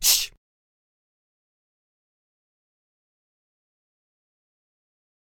shush1.wav